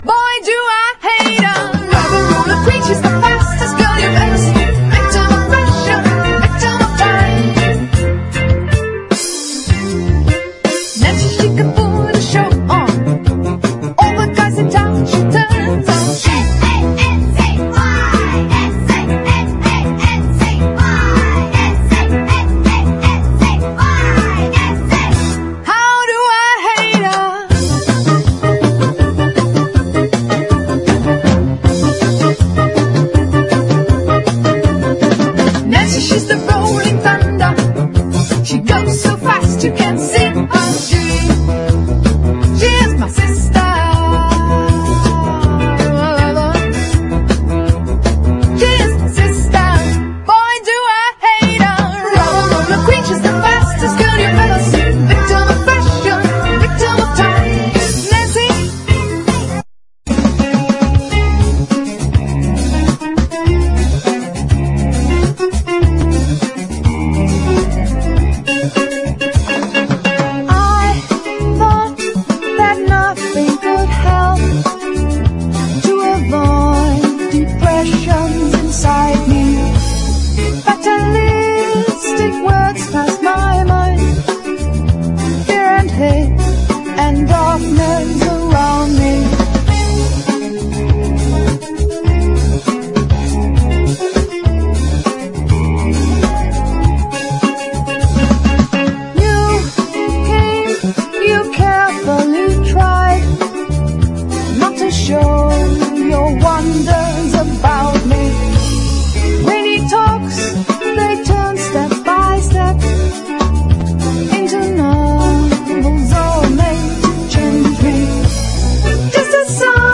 ALT./EXPERIMENTAL
宅録アウトサイダー・ポップ！